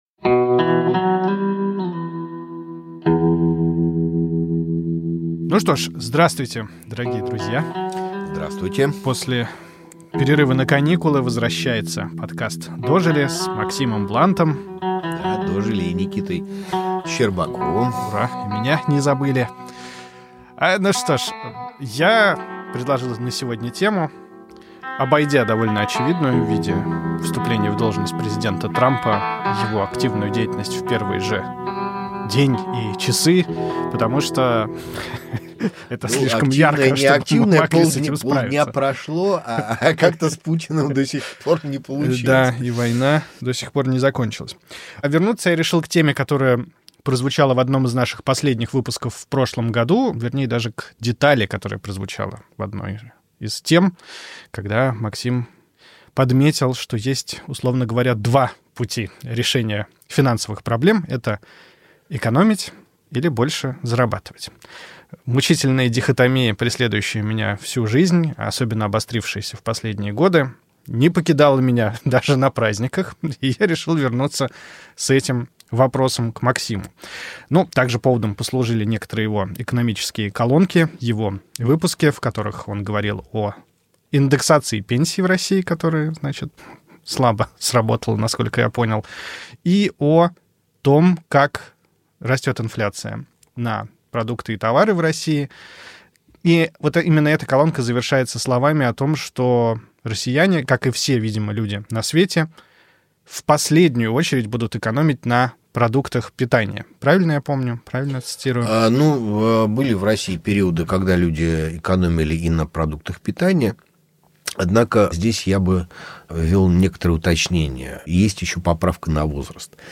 остаются на месте в уютной студии